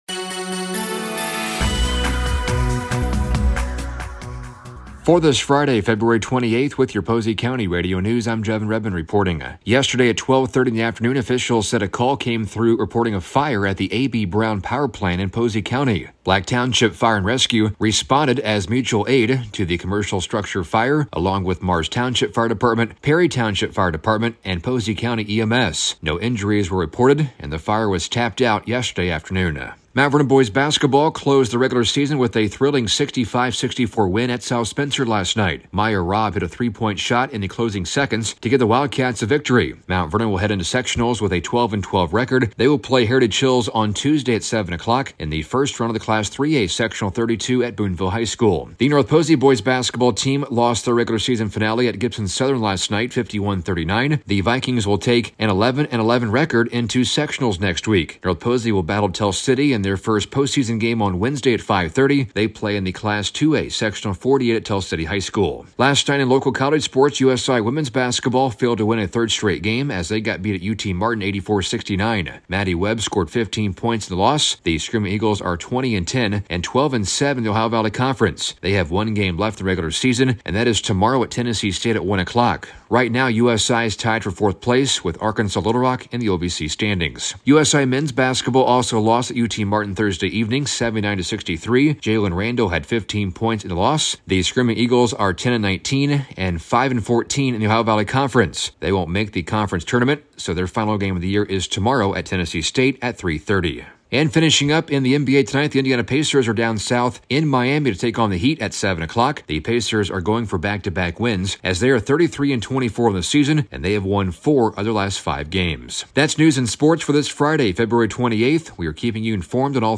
Local News: Friday February 28th 2025